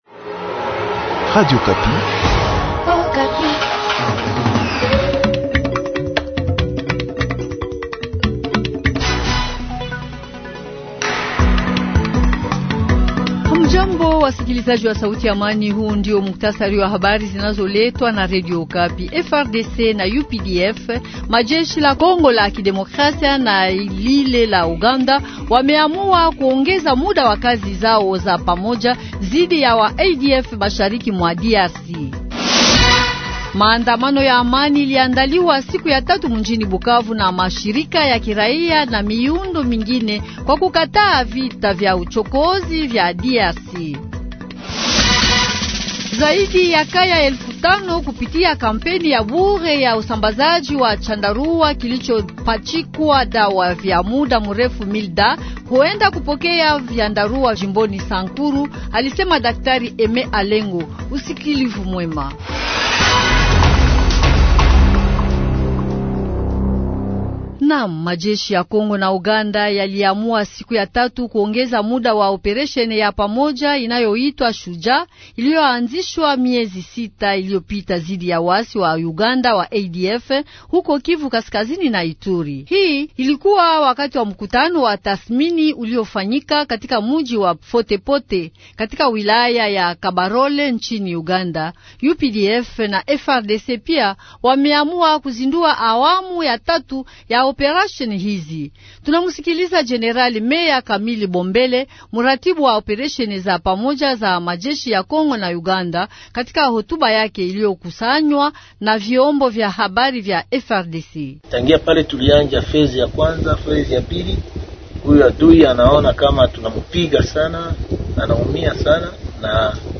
Journal Du Matin